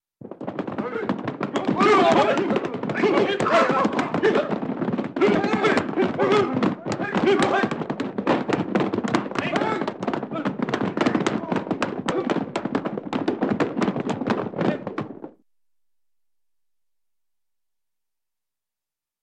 На этой странице собрана коллекция забавных звуков мультяшного бега — от быстрых шлепающих шагов до комичных \
Звук легкой топотни мультяшной толпы